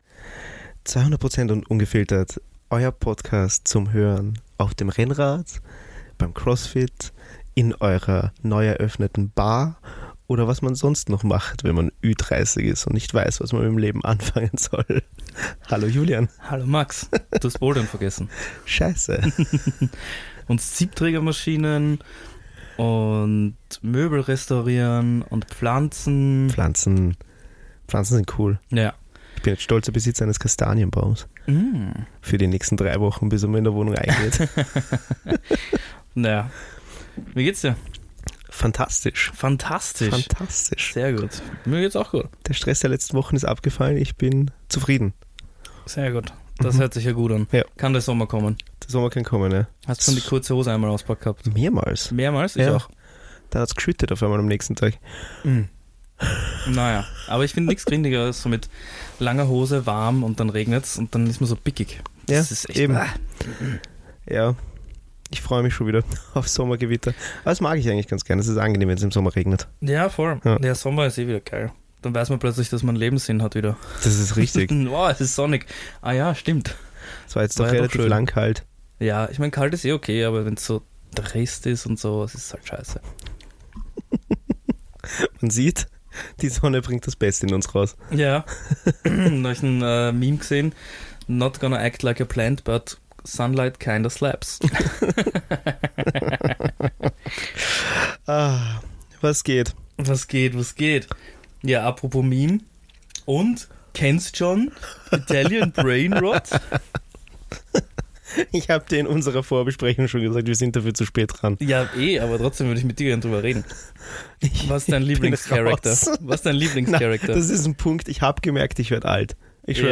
WIR WISSEN AUCH NICHT, WAS ZWISCHEN MINUTE 7 und 12 MIT DEM TON NICHT STIMMT!